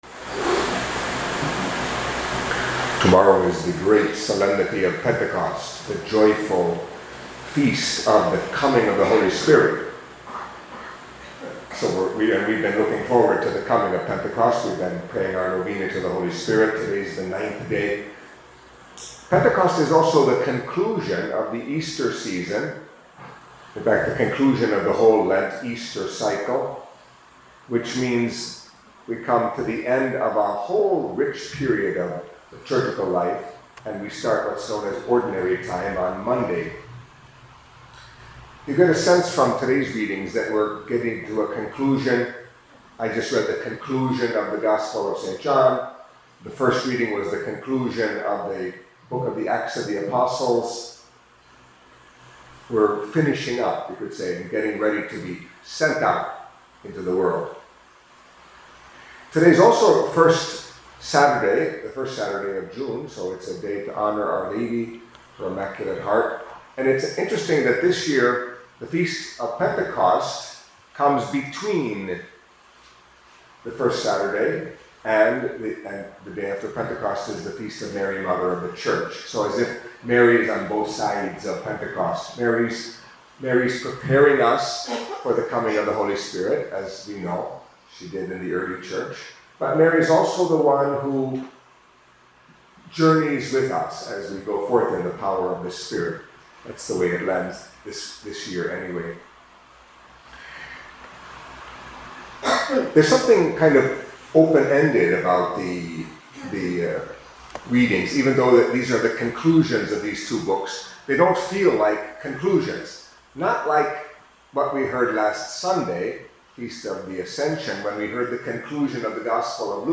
Catholic Mass homily for Saturday of the Seventh Week of Easter